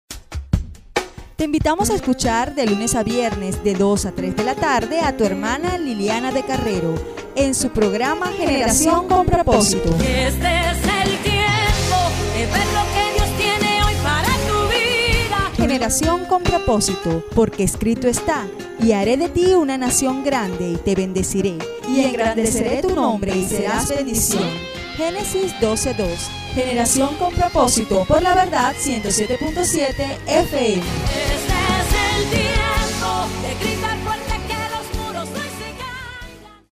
Voice off, Voice over, productor.
Kein Dialekt
Sprechprobe: Sonstiges (Muttersprache):